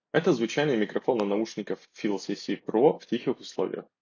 Качество микрофона на 7/10, в шумных условиях не отсекает шум и ловит много лишнего звука. Сам тембр голоса чрезмерно компрессированный.
В тихих условиях: